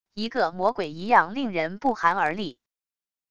一个魔鬼一样令人不寒而栗wav音频